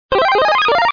SFX超级玛丽吃蘑菇或是花音效下载
SFX音效